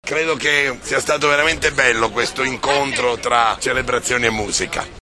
Bagno di folla per i Ministri Ignazio La Russa e Giorgia Meloni, questo pomeriggio al Chiosco Ruffini tra l’omonima via e corso Orazio Raimondo a Sanremo, in occasione della deposizione di una corona al monumento di tutti i Caduti.